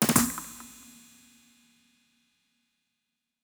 Countdown GO (1).wav